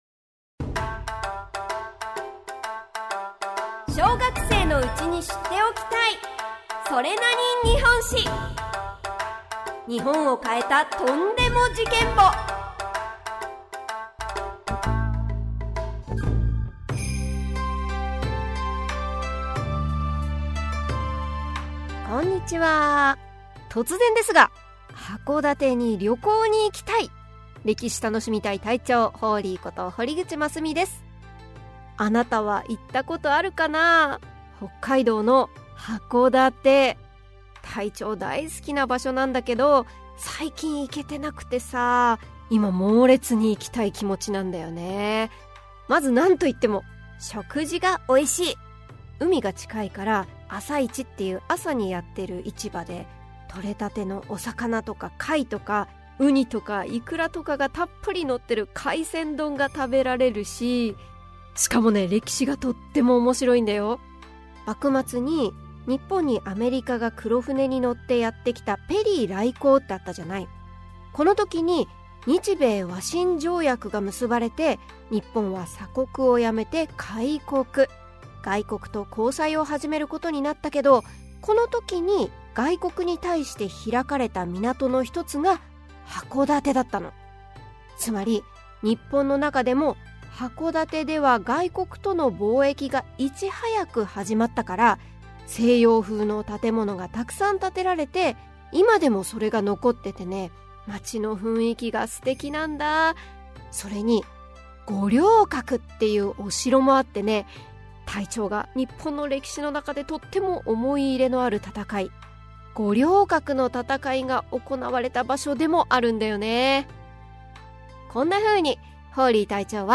[オーディオブック] それなに？日本史 Vol.27 〜戊辰戦争〜五稜郭の戦い編〜